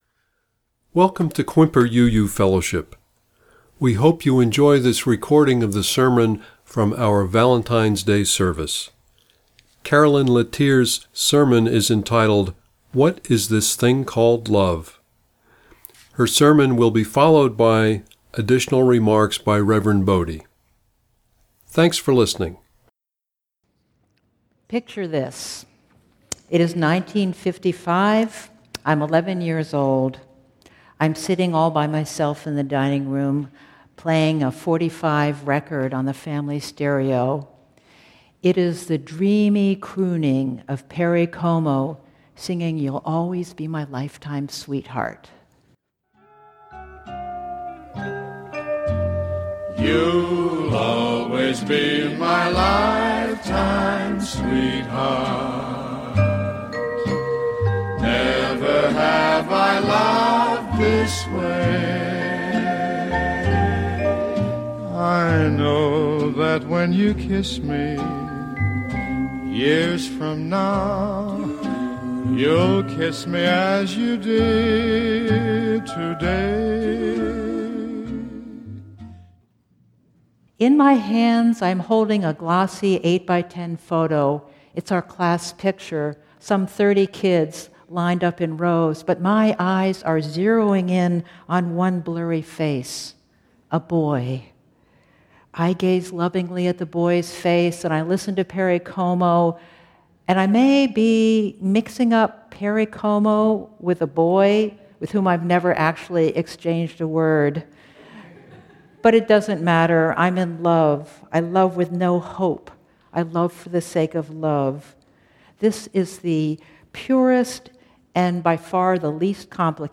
Click here to listen to the reading and sermon.